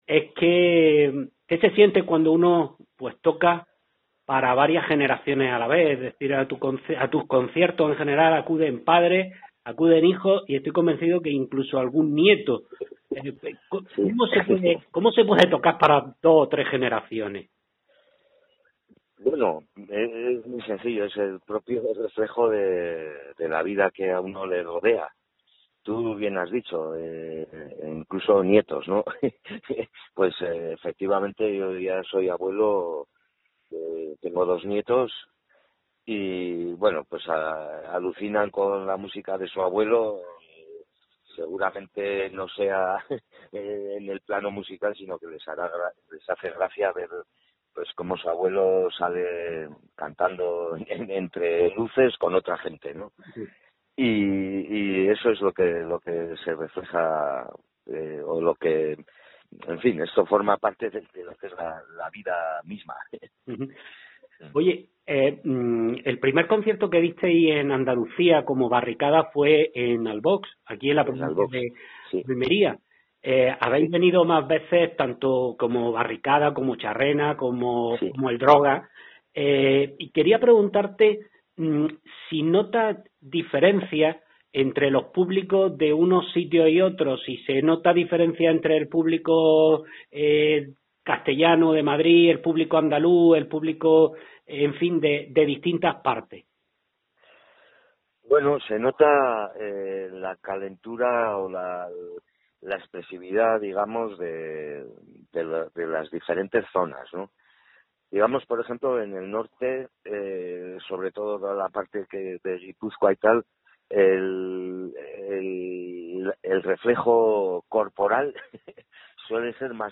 eldrogasentrevista.mp3